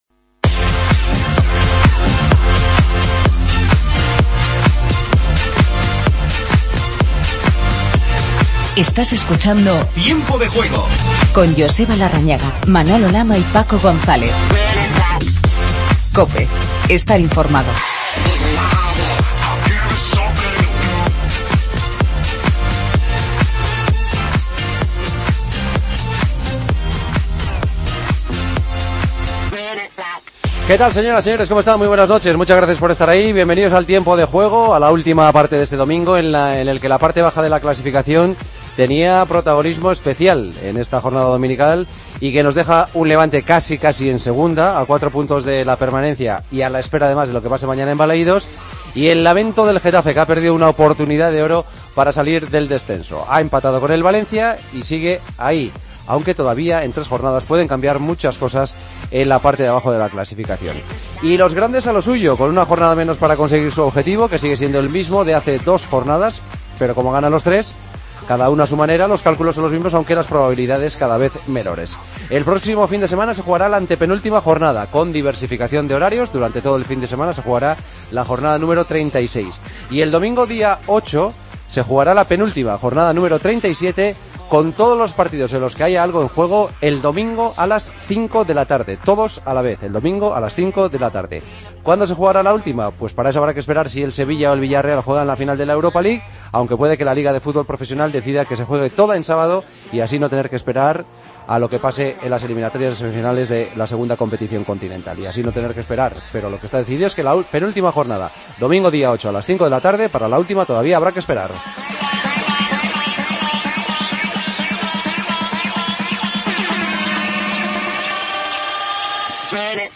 Conocemos la última hora de Barcelona, Atlético y Real Madrid tras sus victorias del sábado. Rossi, campeón de Moto GP en el GP de España de motociclismo. Entrevista a Marc Márquez.